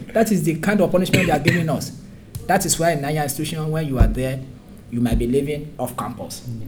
S1 = Bruneian female S3 = Nigerian male S4 = Pakistani male
The problem arises because of the absence of [h] at the start of higher .